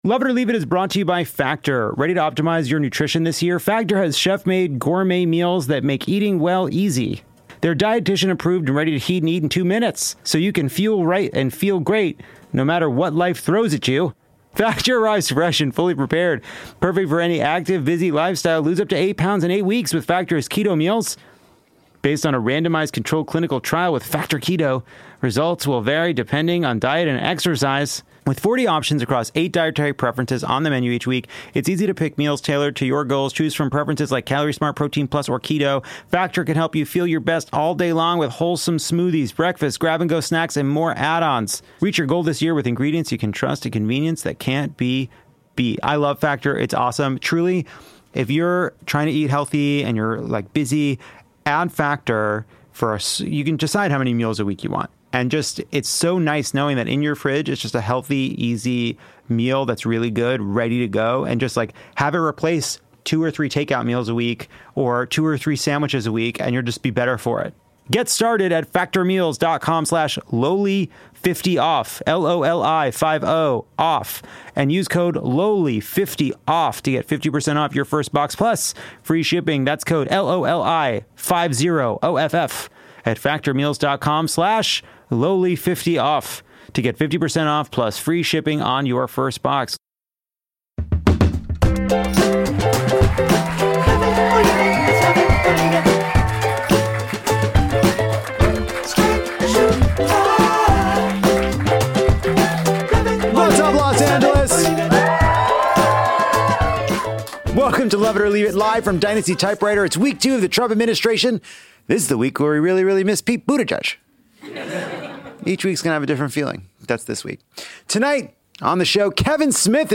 This week, Bernie refuses to baby RFK Jr. and the federal funding freeze sends everyone into a meltdown. Director Kevin Smith stops by to take us down Memory Lane which runs straight through Hollywood. And at long last Lovett sees Emilia Perez and reveals the truth about this film and the French writ large.